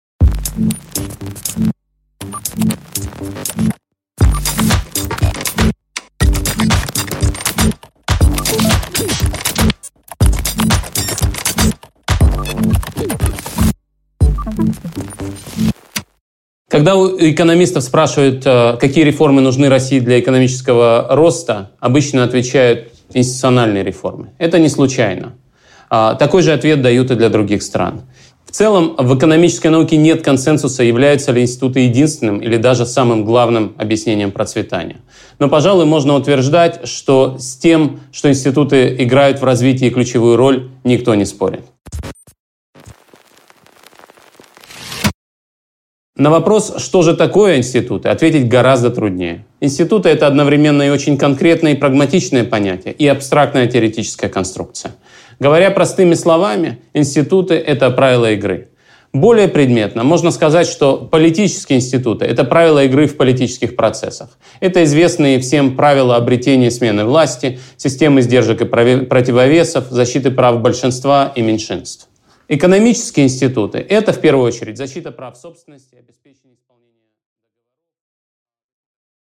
Аудиокнига Правила игры: что такое институты и зачем они нужны? | Библиотека аудиокниг